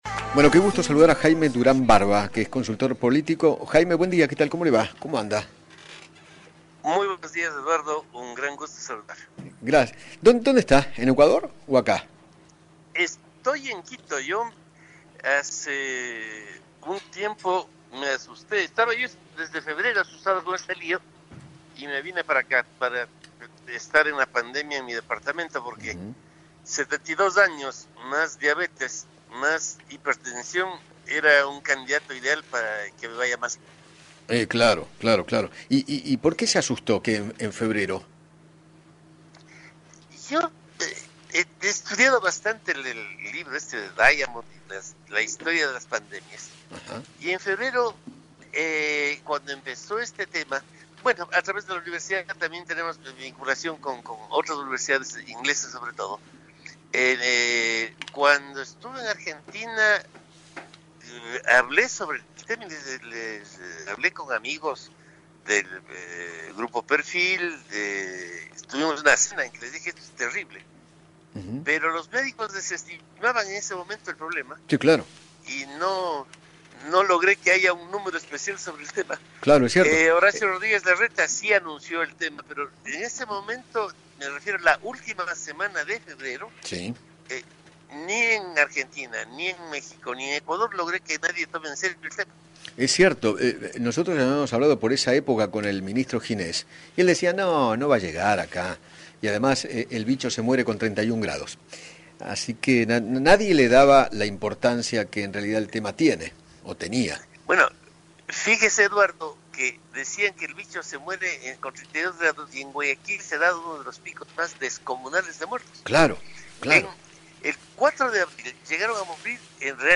Jaime Durán Barba, consultor político, dialogó con Eduardo Feinmann sobre la evolución de la pandemia en el mundo y se refirió a los tratamientos con medicamentos importados que está realizando Ecuador para controlar el avance del Coronavirus. Además, habló de las polémicas excarcelaciones.